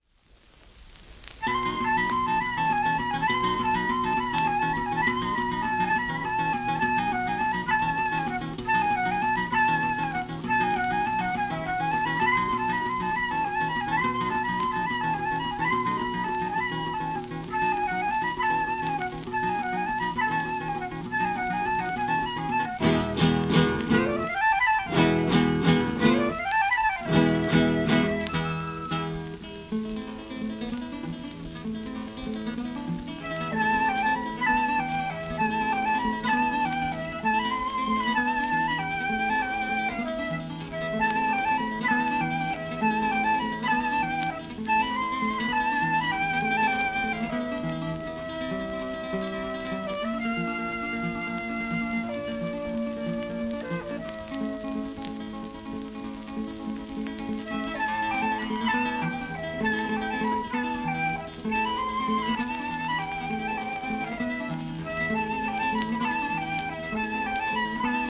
フルートとギターのデュオ、もう片面はそれぞれ共演者を変えた短い小曲３曲